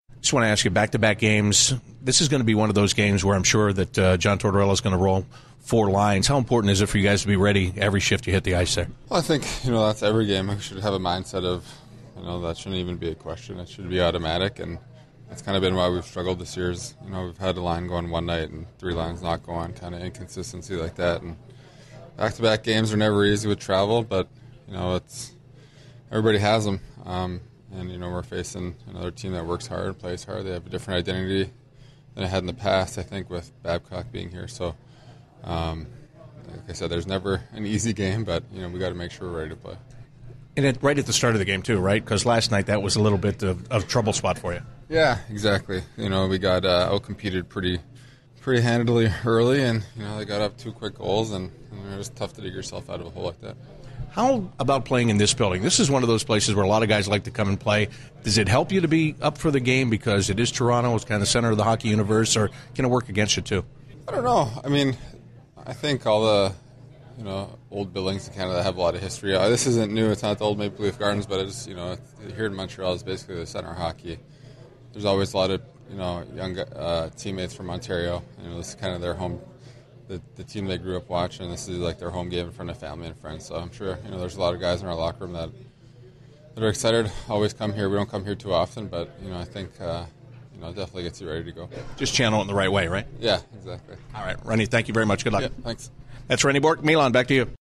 Rene Bourque Pre-Game 01/13/16